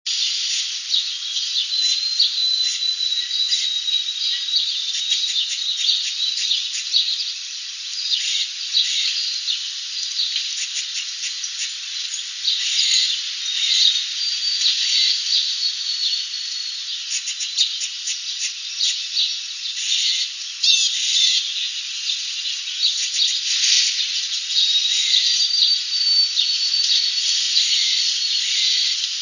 White-throated Sparrow, Hall Avenue, Perth Amboy, NJ, 4/13/02, (114kb) lots of city background noise but good illustration of "pitch switching".  This clip has three sequences:  the first sequence begins with an introductory note at approximately 3300 hz jumping to 4100 and tapering down in the "trill" to 3900; the second begins at 3700 jumps to 4600 and tapers down to 4400; the third begins at 3777, jumps to 4700 and tapers down to 4370 herz.